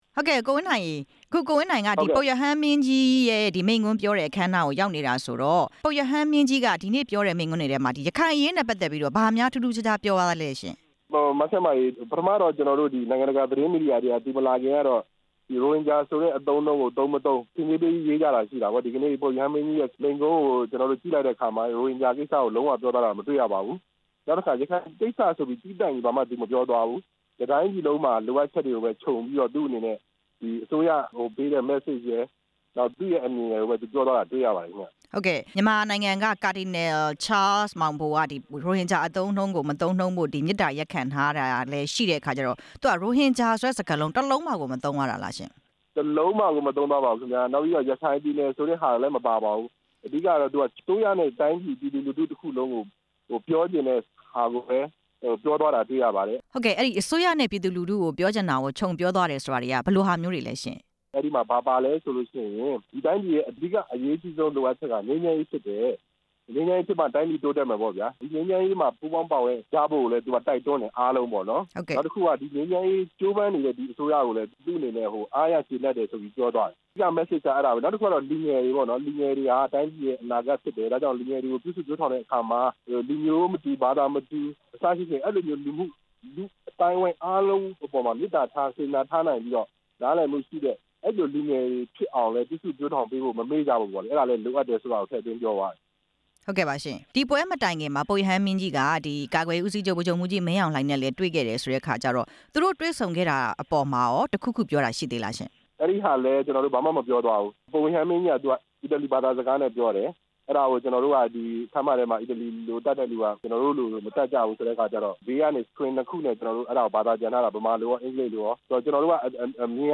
ပုပ်ရဟန်းမင်းကြီး ဖရန်စစ်ရဲ့ မိန့်ခွန်းအကြောင်း ဆက်သွယ်မေးမြန်းချက်